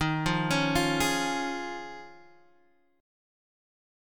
D#7sus2sus4 chord